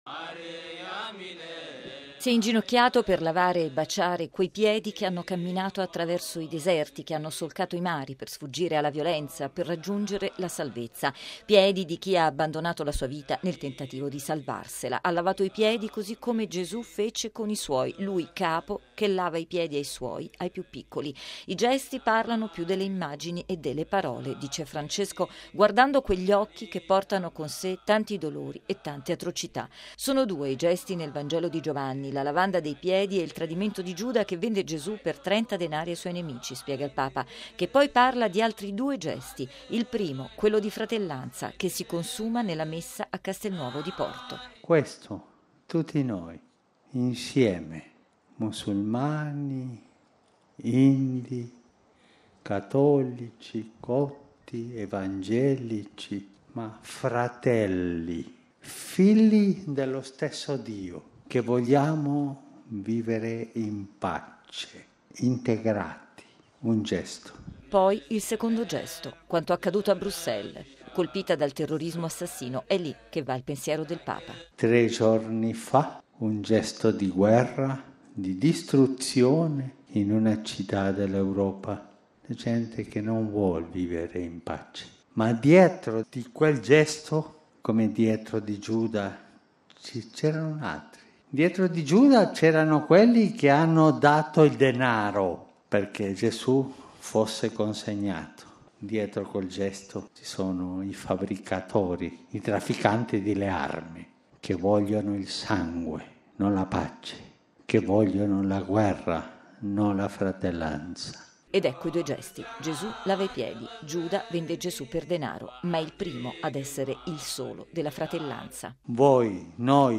E’ stato questo il forte grido di Francesco, durante la Messa in Coena Domini al Cara di Castelnuovo di Porto, il Centro di accoglienza per richiedenti asilo a Nord di Roma. Il Papa ha lavato i piedi a 12 profughi. Siamo tutti fratelli, ha ripetuto più volte, opponendo la fratellanza al sangue versato a Bruxelles da chi vuole la guerra.